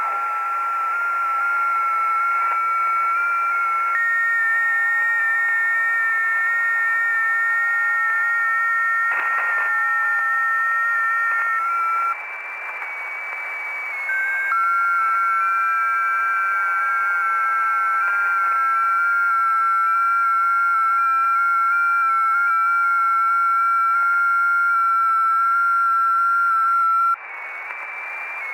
Unbekannter Digimode
• ▲ ▼ Hallo, Ich höre gerade auf 18,100 MHz einen mir unbekannten Digimode.
digimode.ogg